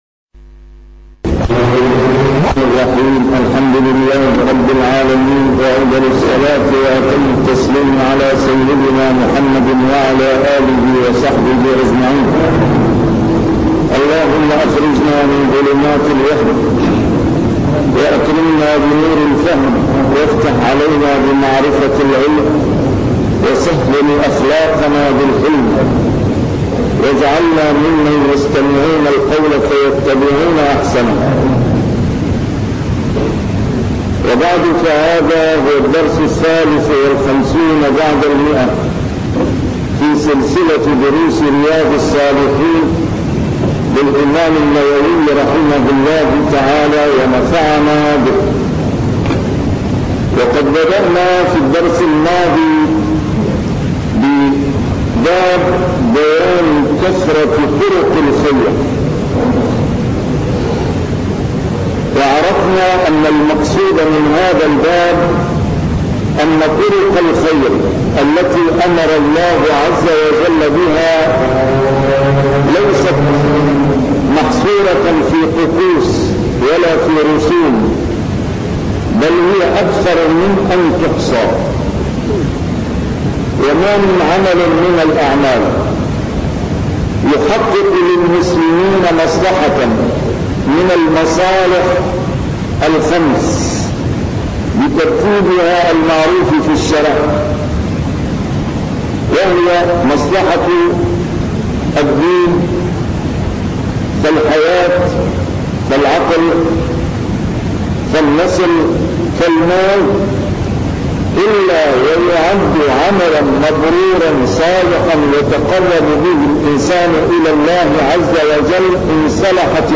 A MARTYR SCHOLAR: IMAM MUHAMMAD SAEED RAMADAN AL-BOUTI - الدروس العلمية - شرح كتاب رياض الصالحين - 153- شرح رياض الصالحين: كثرة طرق الخير